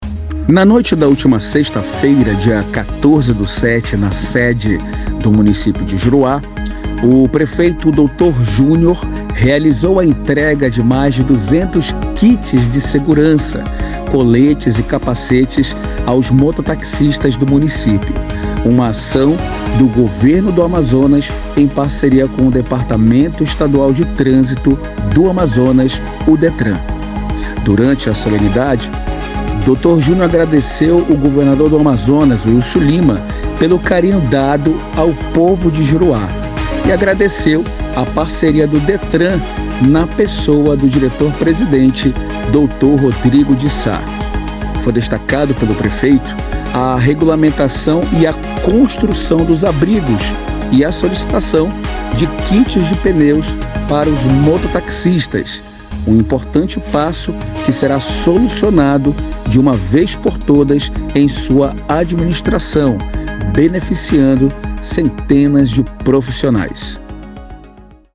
SONORA PAUTA:
Durante a solenidade Dr. Júnior agradeceu o Governador do Amazonas Wilson Lima pelo carinho dado ao povo de Juruá, agradeceu a parceria do Departamento Estadual de Trânsito do Amazonas na pessoa do Diretor Presidente Dr. Rodrigo de Sá.
SONORA-DR.-JUNIOR-KIT-DETRAN.mp3